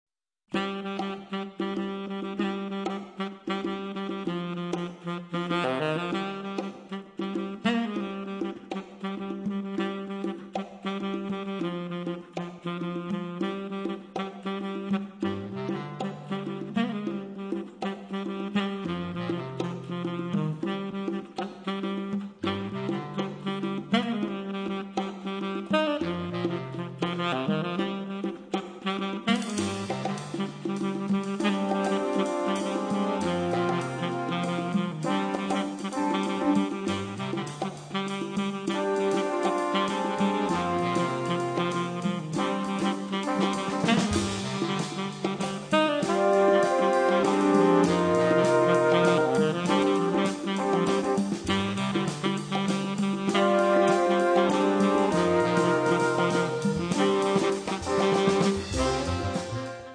clarinetto basso
percussioni